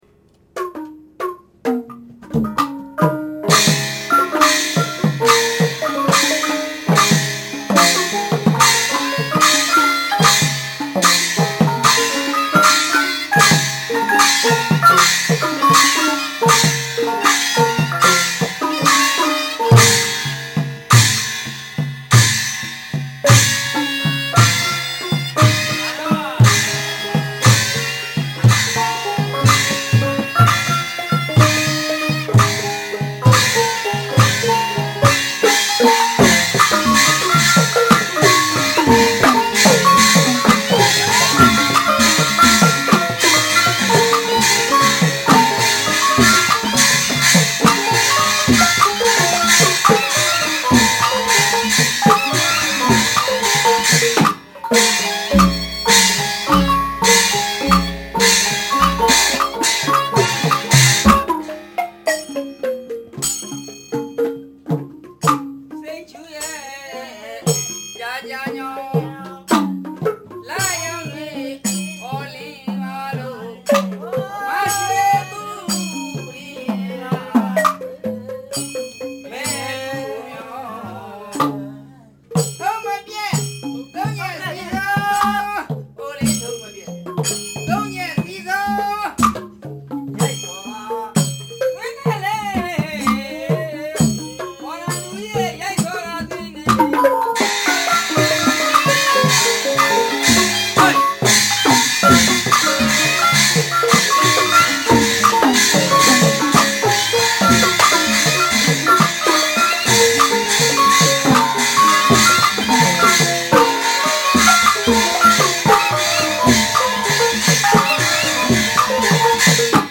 The musicians open the performance with a sort of overture. By beating the suspended knobbed gong (maung), clashing the cymbals, and beating a fast drum rhythm, a musical interpretation is given of the repeated destruction and recreation of the earth by the elements fire, wind and water.
A set of twenty-one tuned drums in a circular frame is the leading instrument. A shawm and two sets of gongs play the melody.